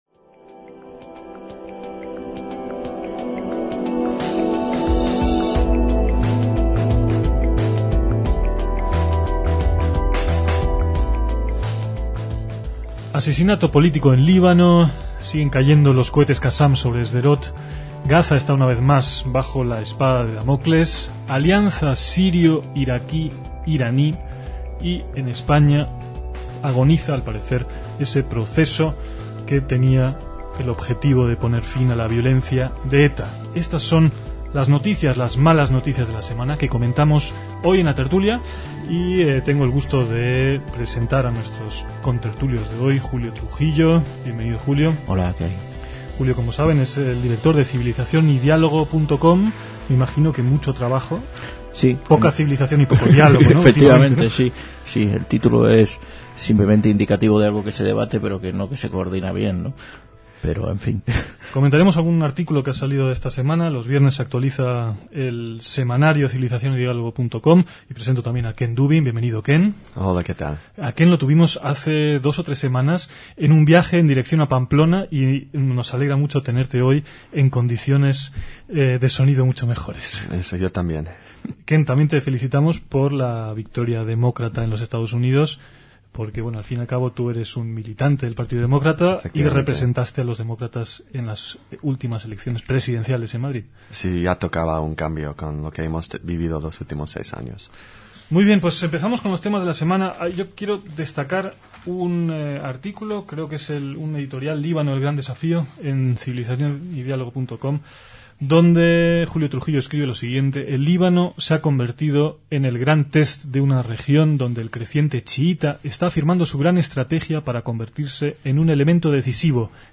DECÍAMOS AYER (25/11/2006) - En la fecha de emisión de este debate, los temas eran un esesinato político en Líbano, los cohetes que seguían cayendo sobre Sderot, Gaza una vez más bajo la espada de Damocles, la alianza sirio-iraqué-iraní y la agonía en España el proceso para poner fin a la violencia de ETA.